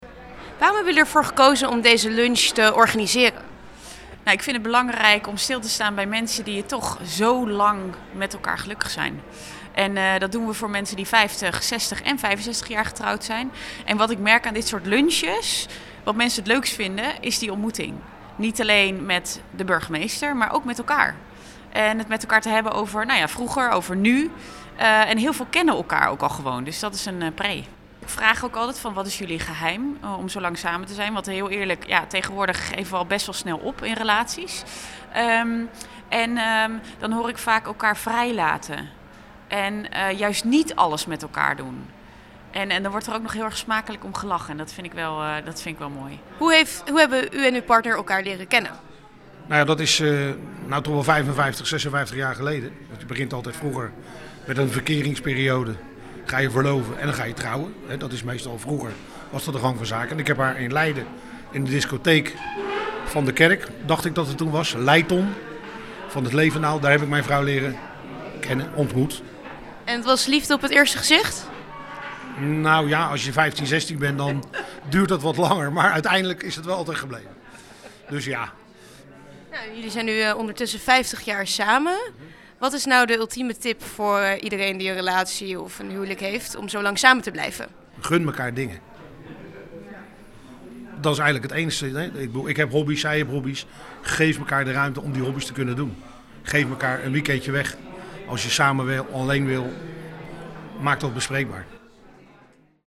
in gesprek met burgemeester Struik en een van de echtparen over het geheim van 50 jaar samen zijn.